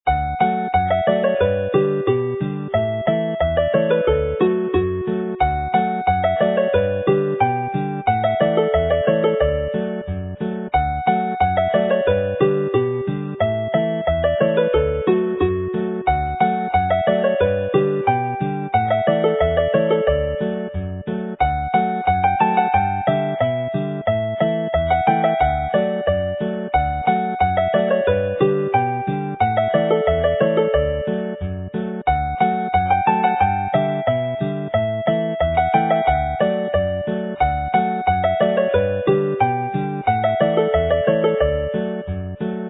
Joio is the South Walian slang Welsh derived from the English enjoy and is the jolliest tune in the set.